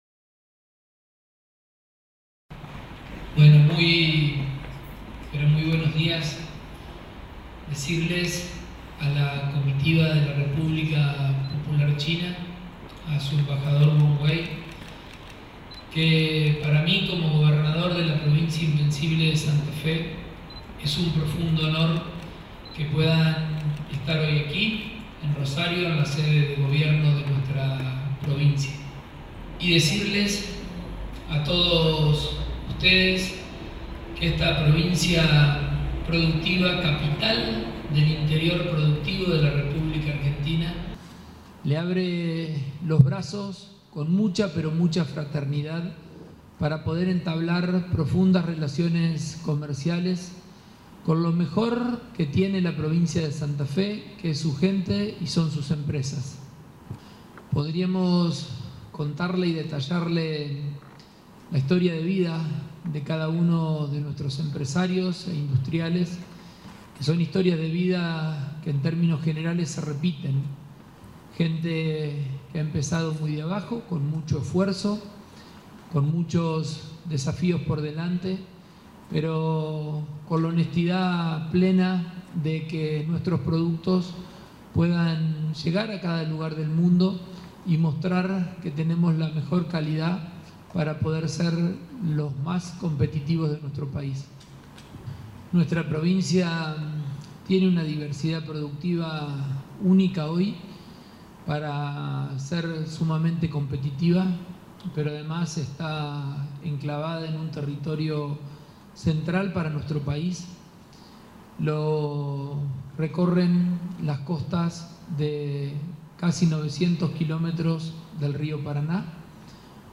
Alocución del gobernador Pullaro